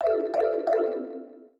Sound effect of Pipe Travel in Super Mario Bros. Wonder
SMBW_Pipe_Travel.oga